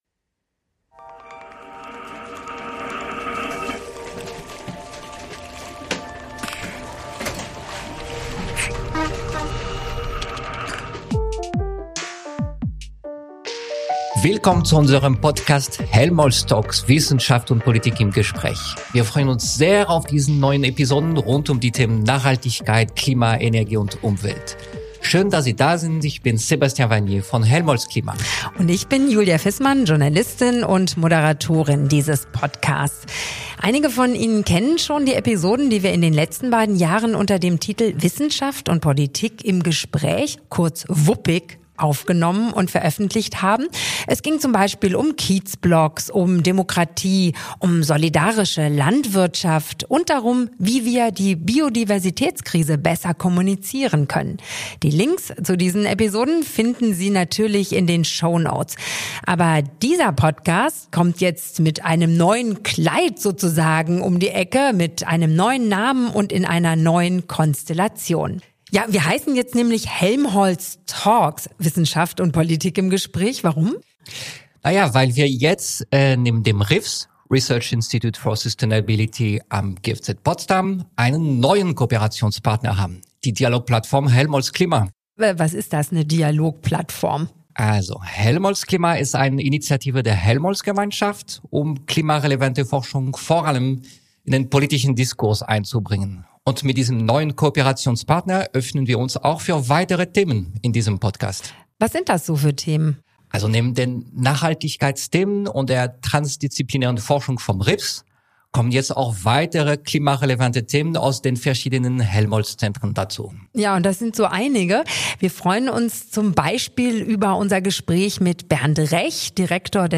diskutieren Wissenschaftler:innen und Politiker:innen über zentrale